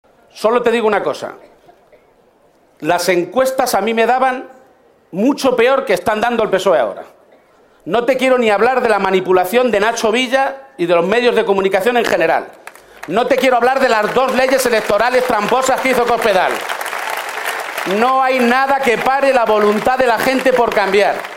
Cerca de tres mil personas acudieron al mitin celebrado en la Caseta de los Jardinillos del Recinto de la Feria de Albacete
Cortes de audio de la rueda de prensa